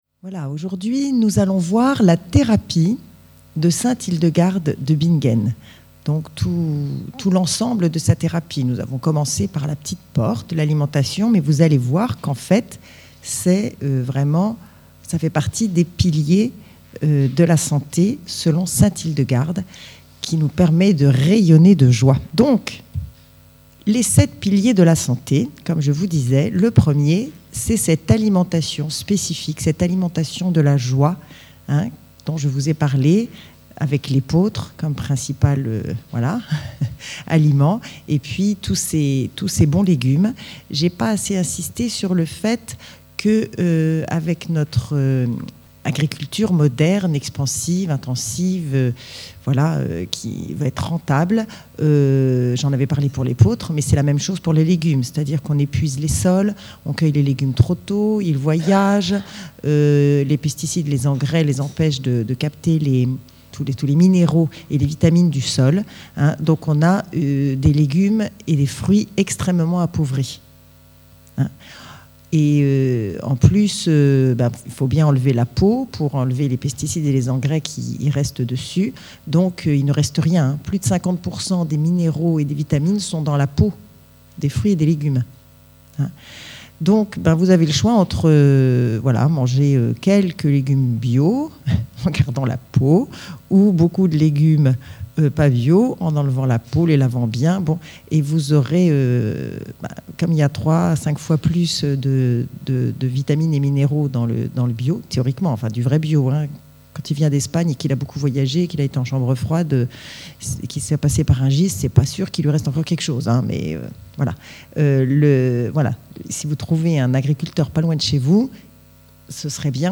6 enseignements regroupés en un seul CD MP3*: